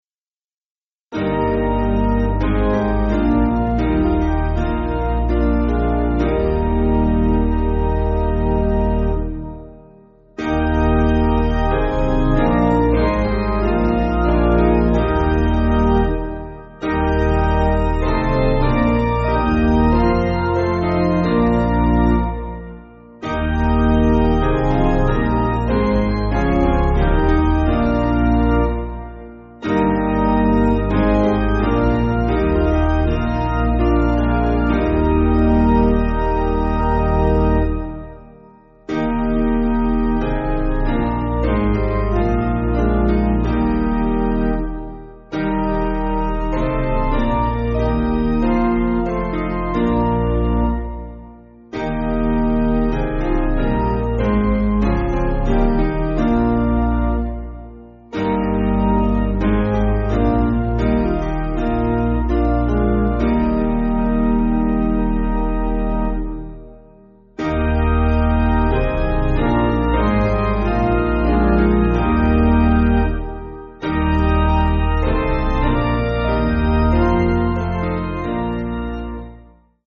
Basic Piano & Organ
4/Eb